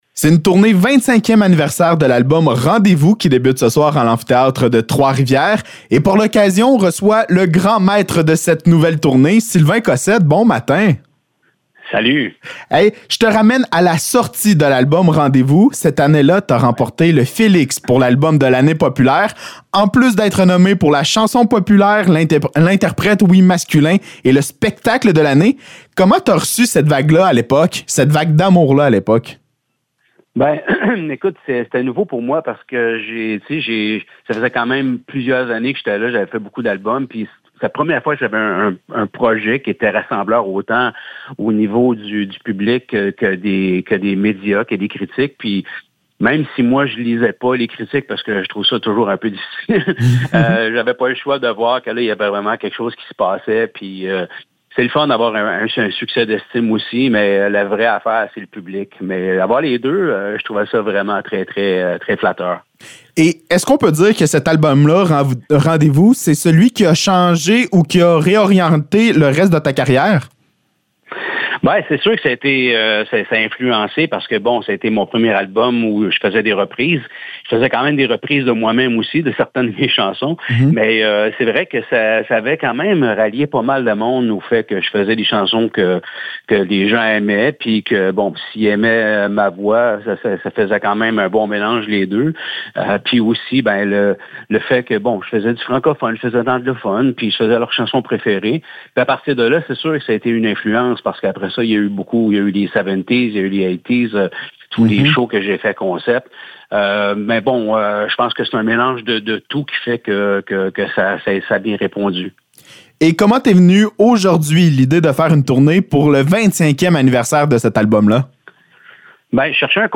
Entrevue avec Sylvain Cossette
Entrevue avec Sylvain Cossette concernant le début de sa toute nouvelle tournée du 25e anniversaire de l’album Rendez-vous.